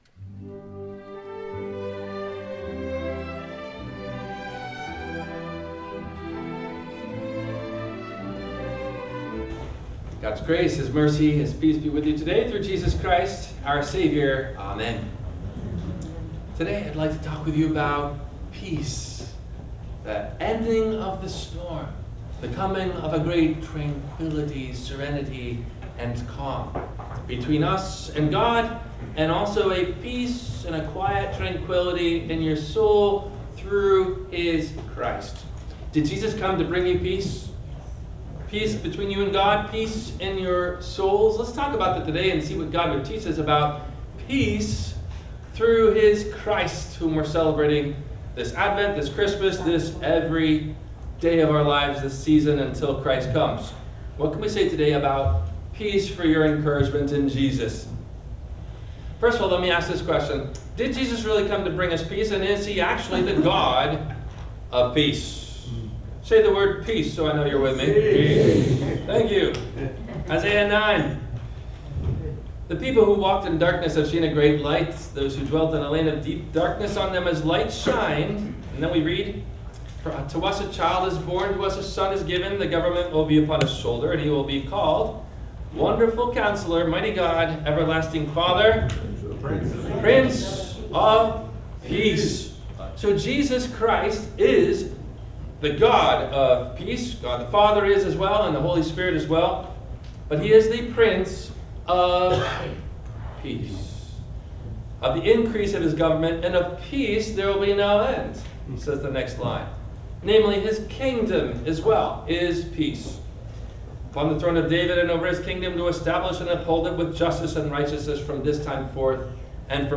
Does Jesus Bring Us … Peace? – WMIE Radio Sermon – December 22 2025